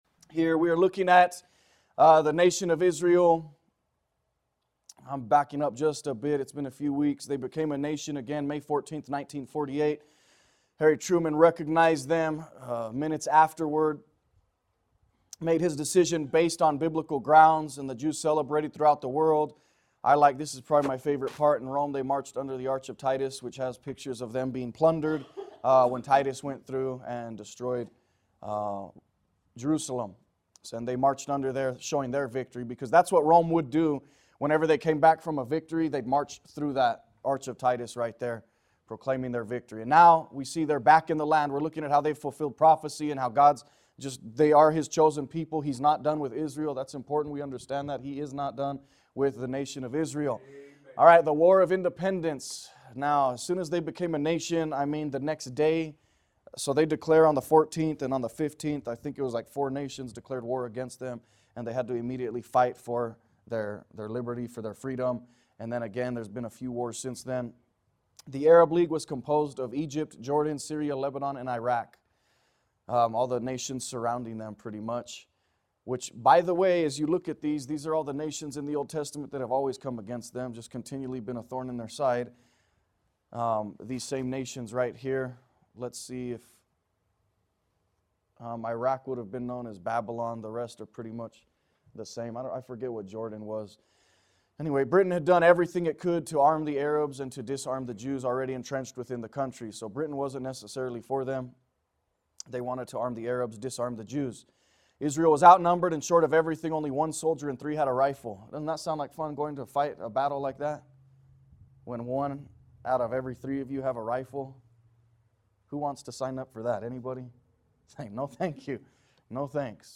A message from the series "Archaeology & the Bible."